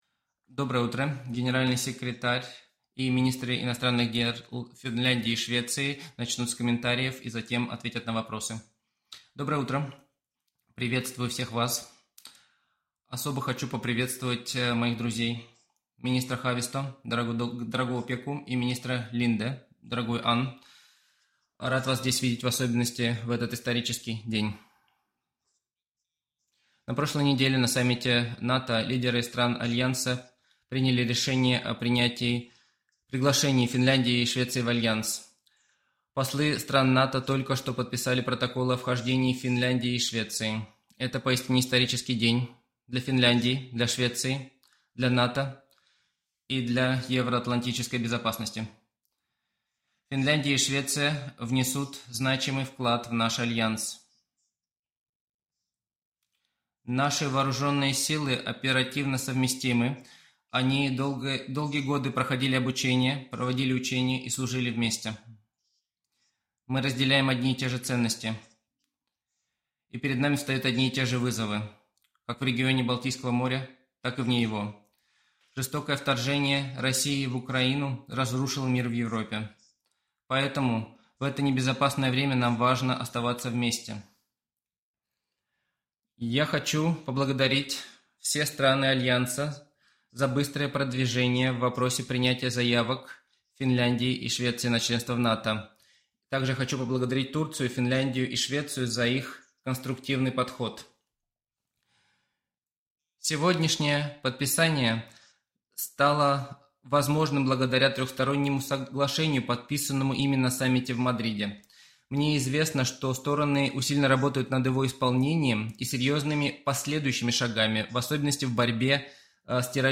Press conference
by NATO Secretary General Jens Stoltenberg, the Minister of Foreign Affairs of Finland, Pekka Haavisto, and the Minister of Foreign Affairs of Sweden, Ann Linde following the signature of the NATO Accession Protocols for Finland and Sweden